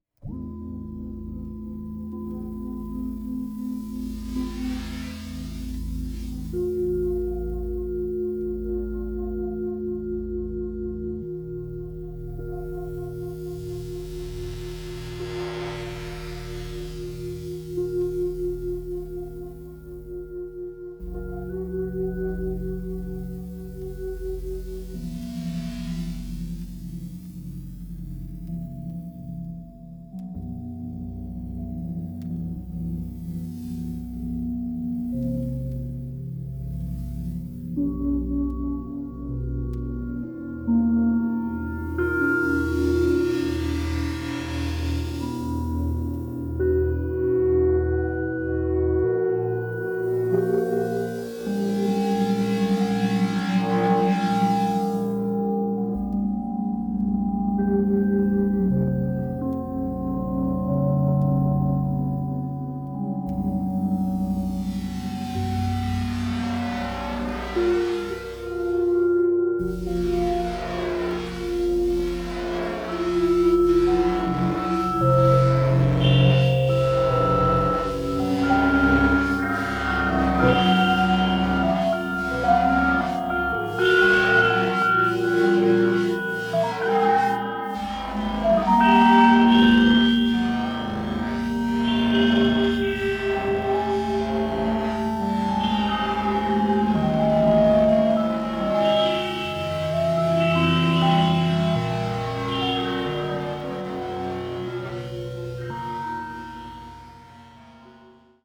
The atonality of the score is profound